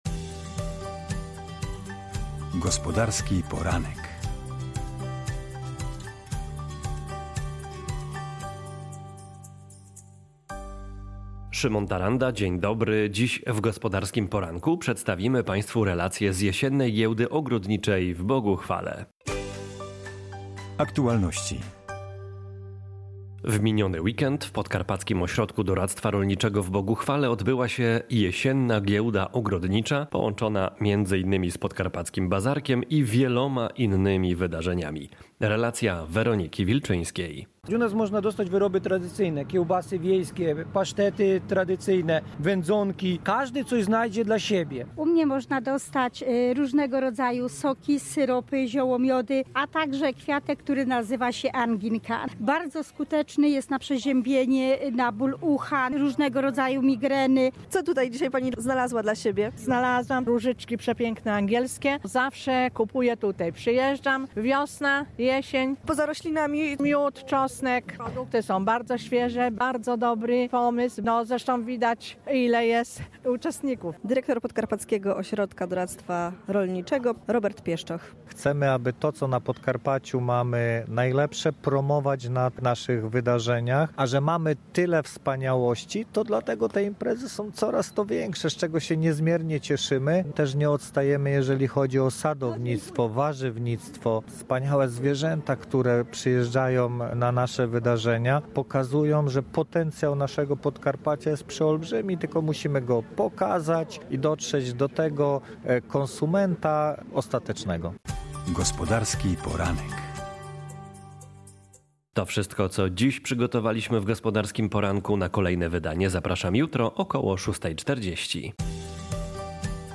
Dziś w „Gospodarskim Poranku” przedstawimy relację z Jesiennej Giełdy Ogrodniczej w Boguchwale.